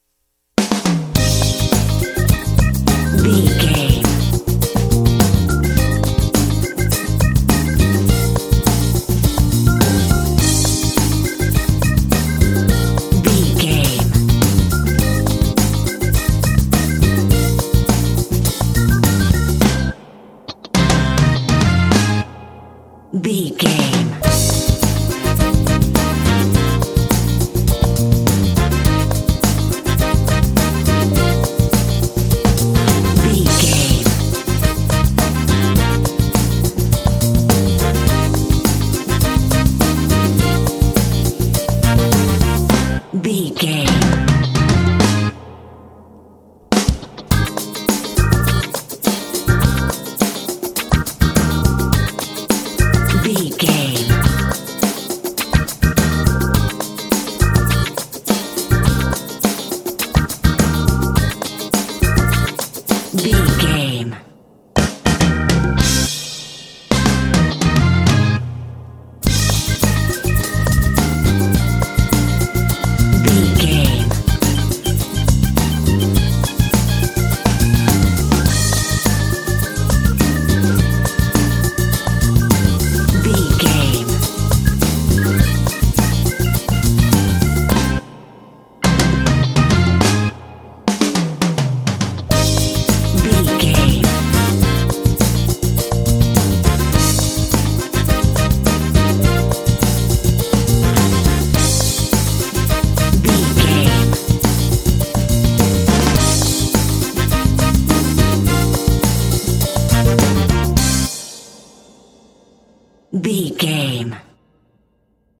Uplifting
Aeolian/Minor
groovy
driving
energetic
electric organ
electric piano
drums
bass guitar
electric guitar
brass